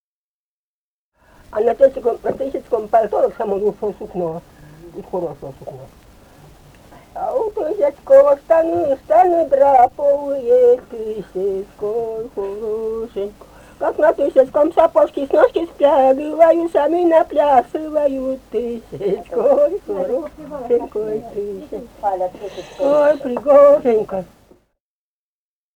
«Тысяцкой хорошенькой» (свадебная).